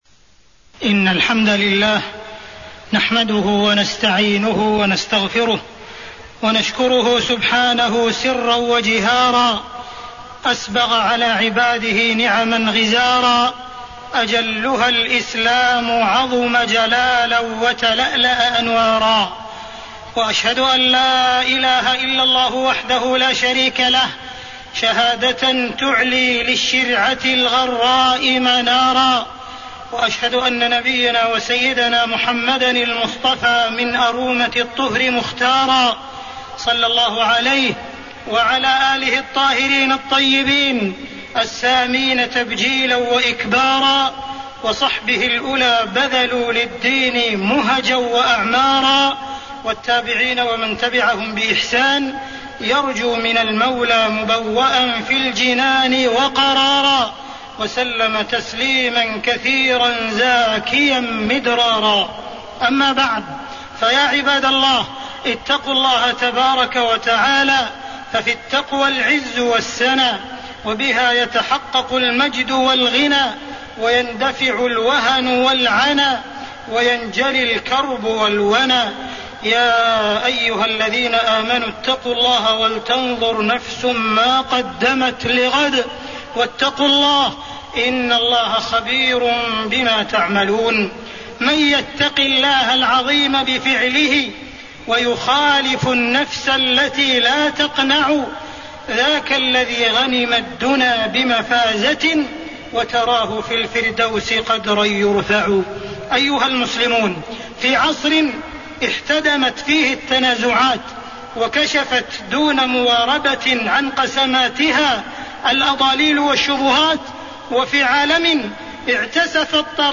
تاريخ النشر ١٩ ربيع الثاني ١٤٣٤ هـ المكان: المسجد الحرام الشيخ: معالي الشيخ أ.د. عبدالرحمن بن عبدالعزيز السديس معالي الشيخ أ.د. عبدالرحمن بن عبدالعزيز السديس الافتراء على الإسلام